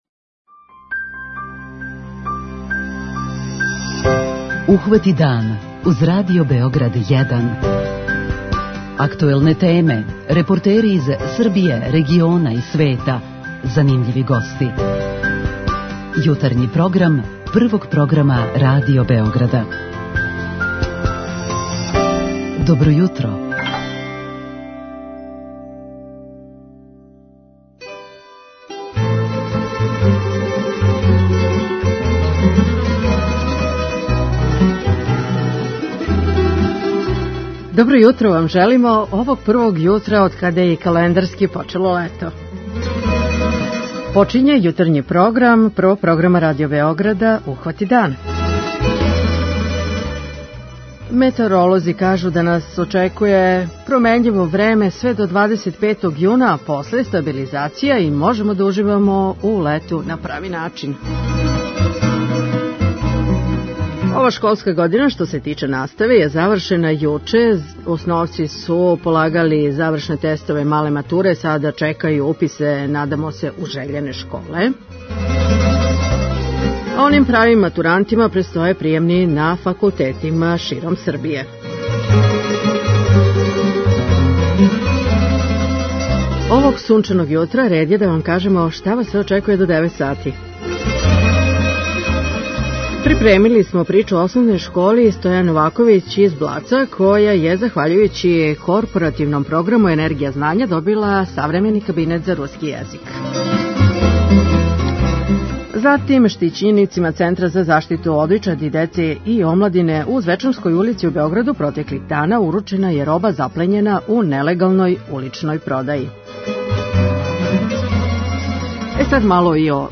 преузми : 40.30 MB Ухвати дан Autor: Група аутора Јутарњи програм Радио Београда 1!